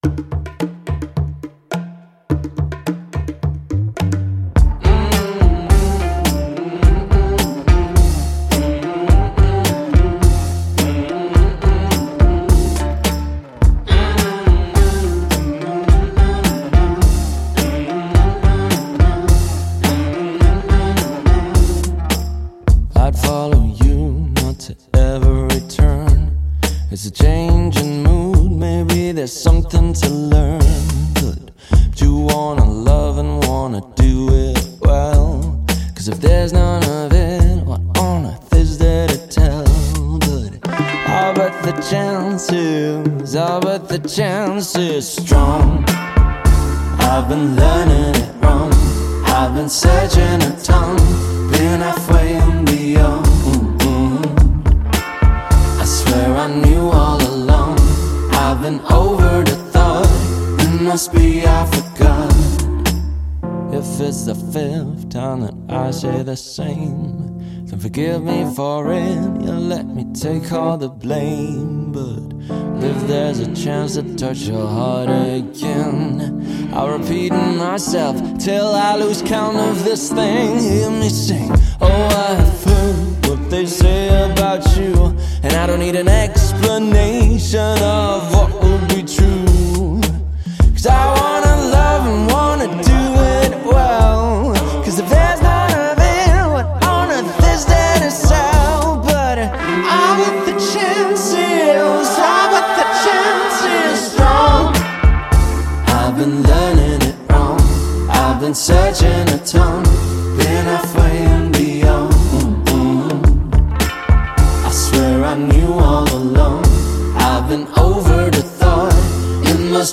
راک Rock